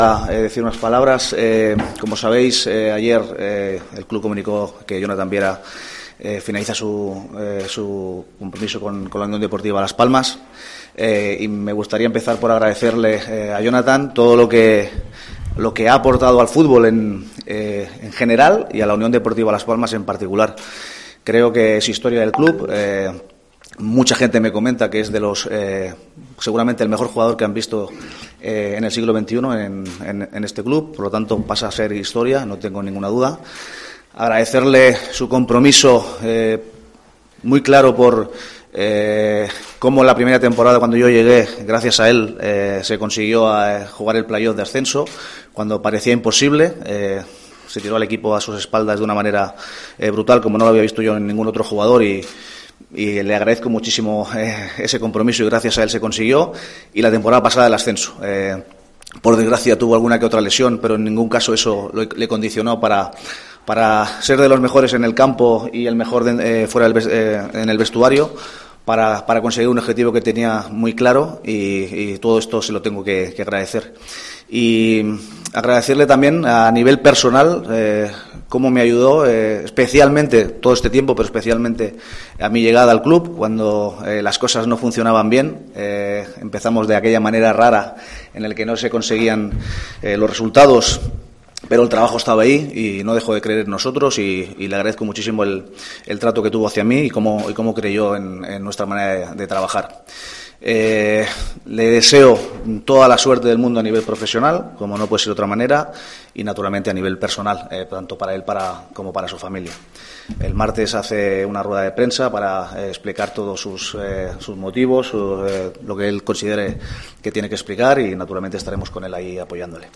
El técnico catalán, sin ser preguntado por este asunto, ha iniciado la rueda de prensa previa al partido contra el Cádiz refiriéndose a Jonathan Viera, aunque sin entrar en detalles de los motivos de la ausencia del jugador de las convocatorias, después de jugar su último partido a principios de octubre pasado ante el Villarreal.